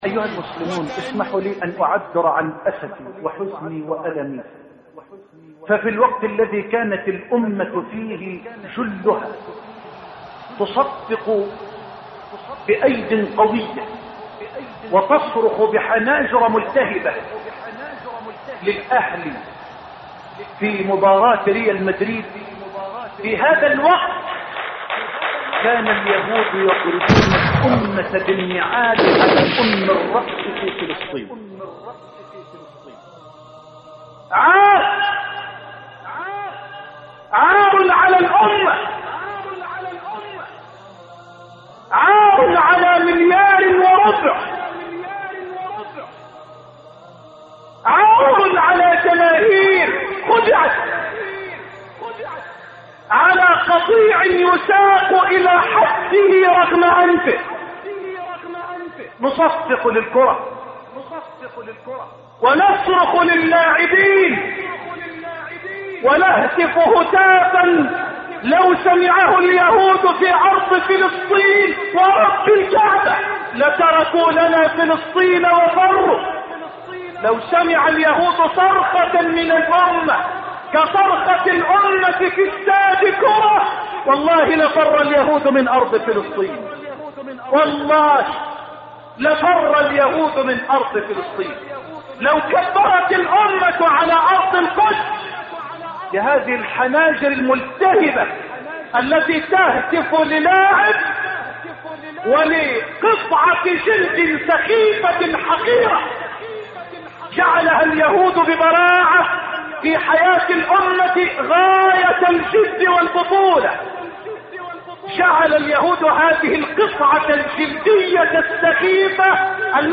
شبكة المعرفة الإسلامية | الدروس | إلى عشاق الكرة ..